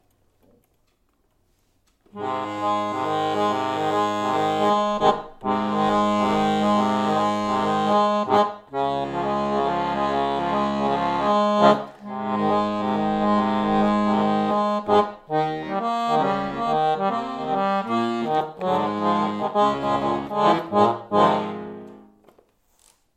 Der Mitschnitt ist aus einer ganz normalen Übesesseion raus, bei der ich grad das...